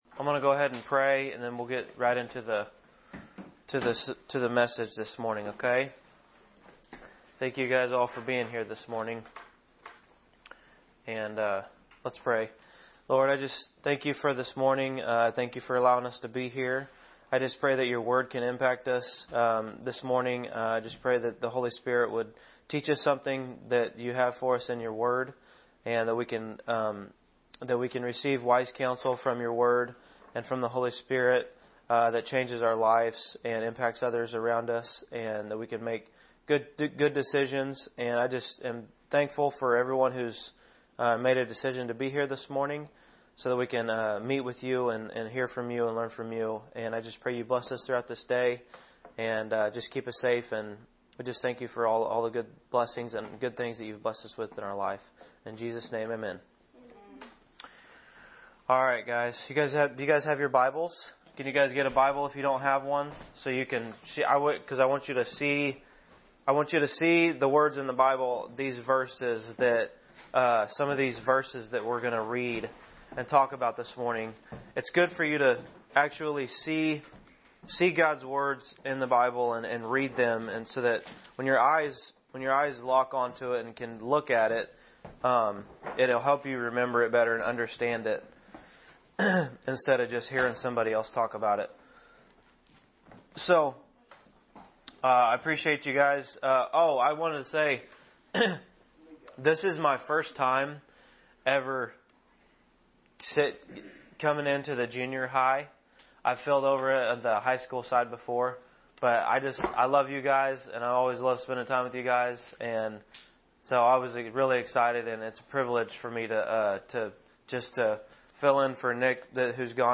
Middle School Guest Speaker